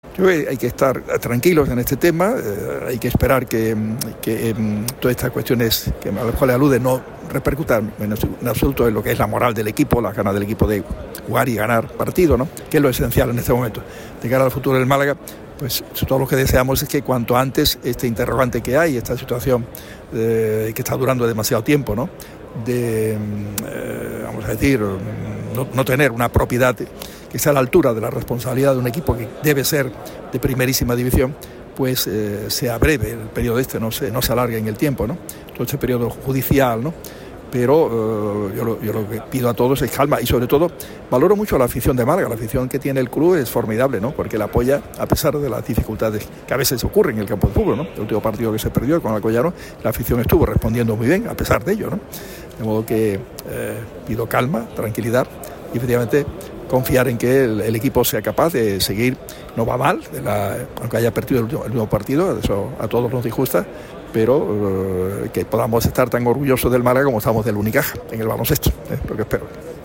Este viernes el equipo de Radio MARCA Málaga ha estado presente en la Feria del Motor en el Palacio de Ferias y Congresos. Allí estuvo el alcalde de la ciudad de Málaga, que atendió brevemente a los medios. El edil respondió a las pancartas en La Rosaleda.